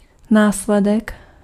Ääntäminen
IPA: [sɥit]